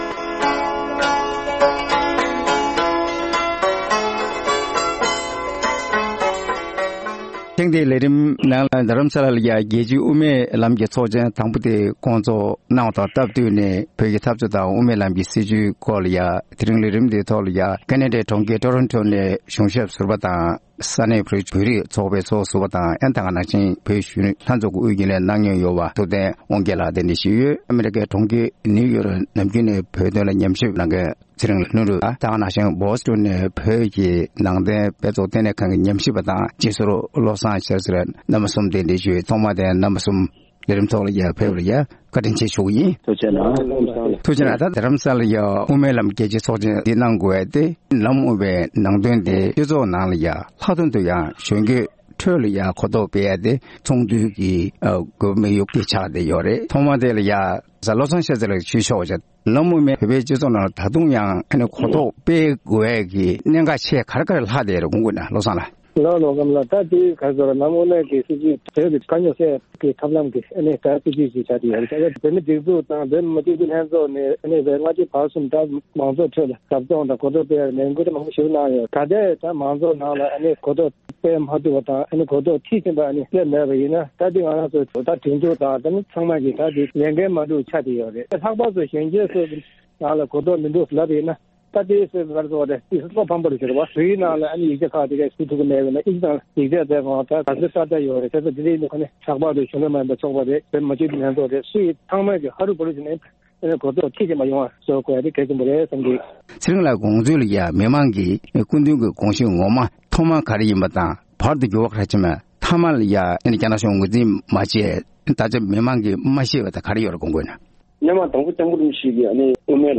༧གོང་ས་མཆོག་གིས་རྒྱལ་སྤྱིའི་དབུ་མའི་ལམ་གྱི་ཚོགས་ཆེན་དུ་བཅར་མཁན་ཡོངས་ལ་དབུ་མའི་ལམ་གྱི་སྐོར་ངོ་སྤྲོད་བཀའ་སློབ་གནང་འདུག